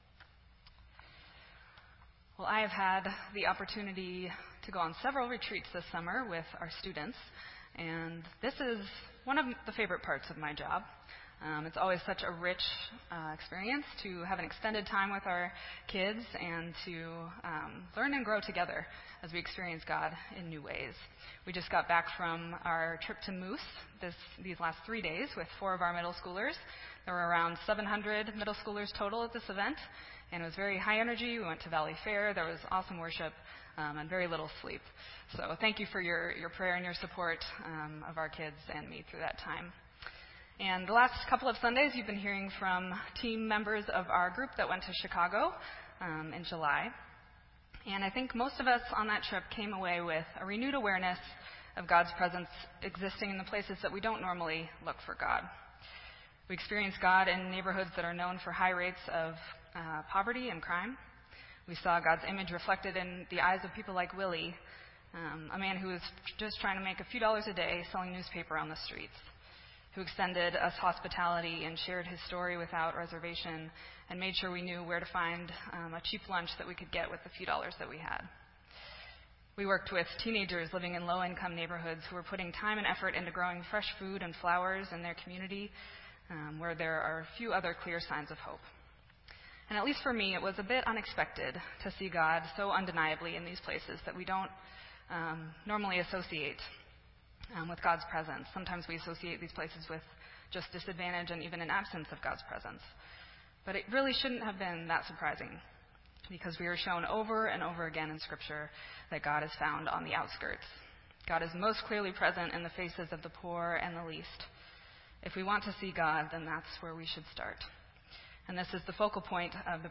This entry was posted in Sermon Audio on August 7